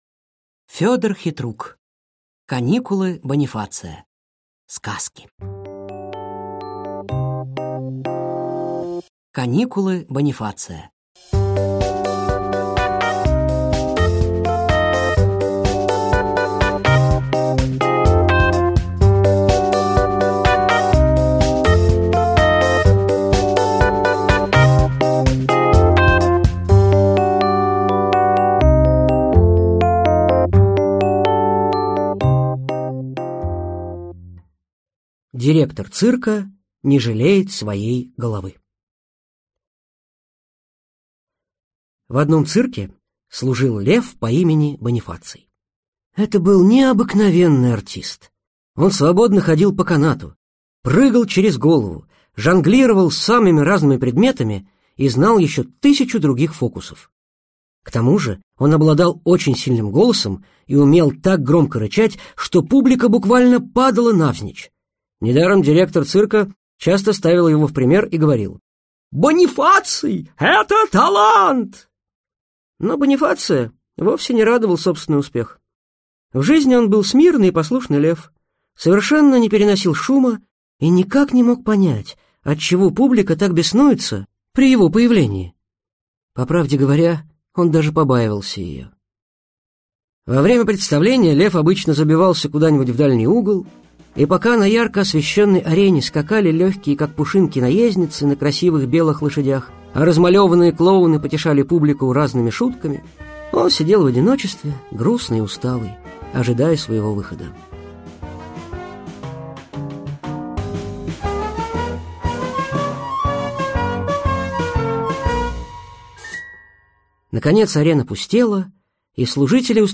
Аудиокнига Каникулы Бонифация | Библиотека аудиокниг